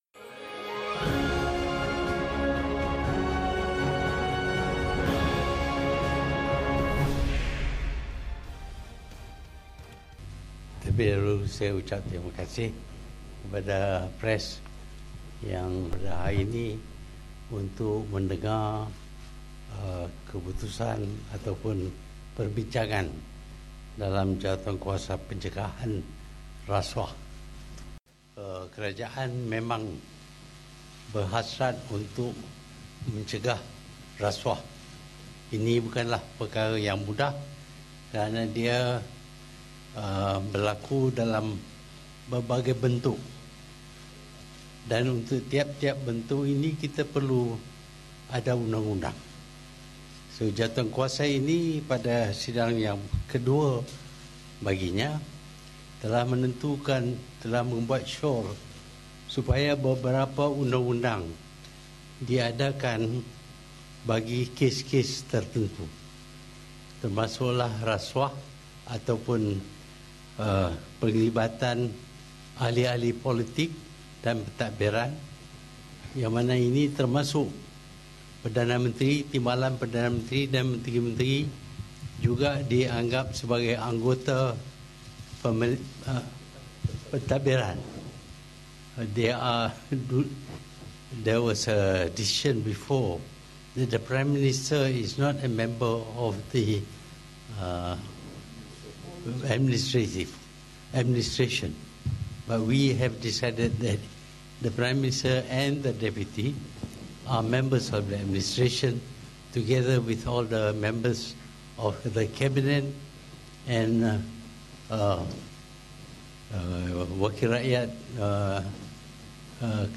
Sidang Media Perdana Menteri, Tun Dr Mahathir Mohamad selepas Mesyuarat Jawatankuasa Khas Kabinet Mengenai Anti Rasuah di Kompleks Perdana Putra, Putrajaya.